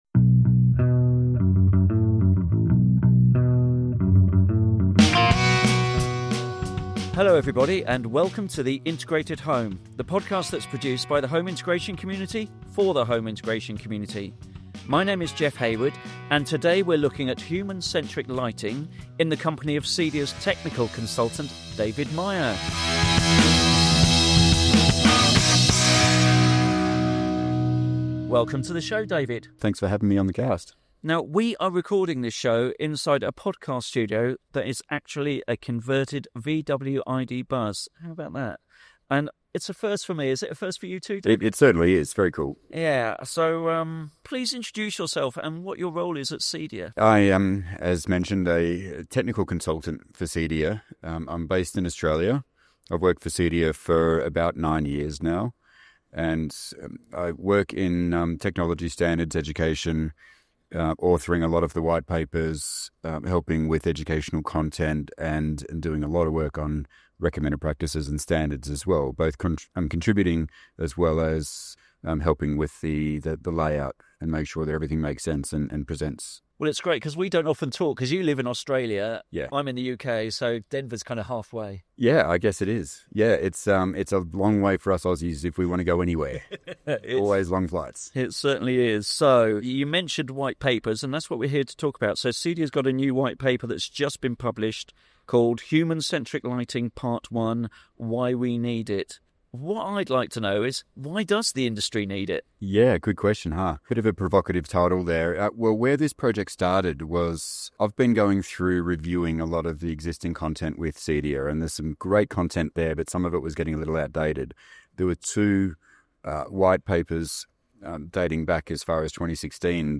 Recorded at the CEDIA Expo in Denver, the conversation dives into the science behind light and its effects on the human body, including circadian rhythms and well-being.